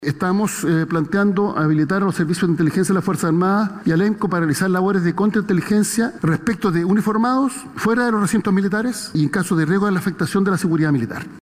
Así lo explicó el secretario de Estado.